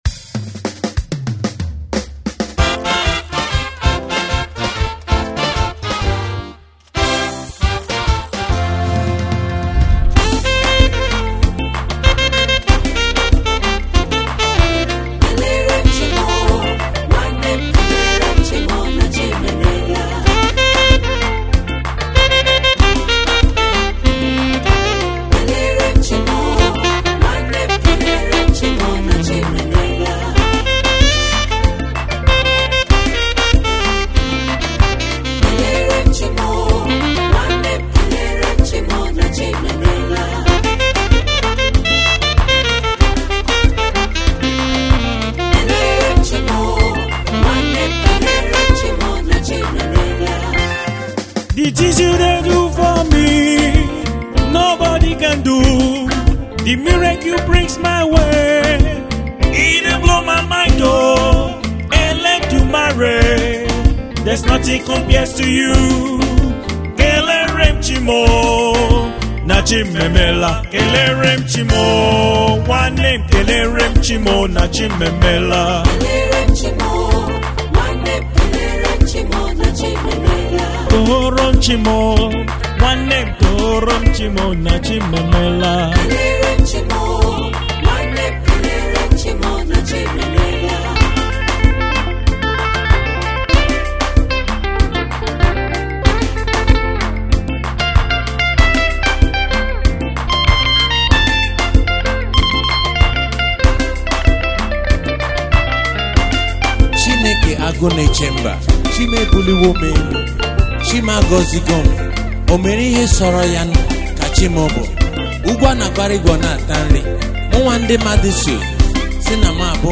Gospel
is a gospel song